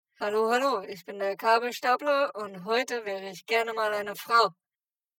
naja es geht ja darum eine schon aufgenommen, wohlgemerkt Sprachaufnahme - nicht Gesang - in eine Frauenstimme umzuwandeln... daher macht das mit der Kopfstimme wenig Sinn.
Also mit einer Mischung aus normalem Pitching und Pitching, welches die Formanten in Ruhe lässt, kann man Männerstimmen schon in Frauen verwandeln und Frauen in Männer.
Könnte auch ein verschnupfter Junge sein. xD Anhänge Kabelstapler.mp3 Kabelstapler.mp3 121,9 KB · Aufrufe: 288